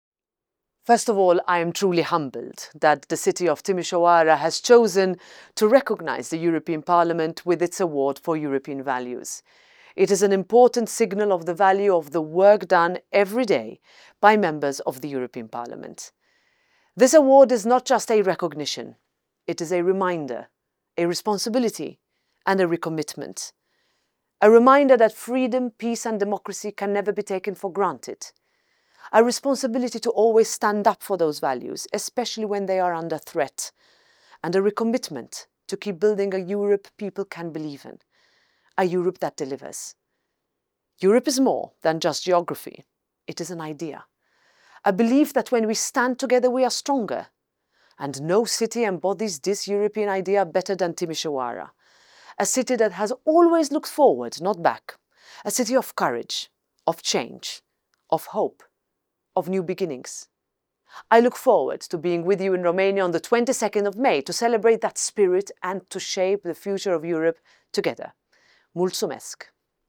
Într-o declarație pentru Radio Timișoara, Roberta Metsola spune că Europa reprezintă convingerea că împreună suntem mai puternici.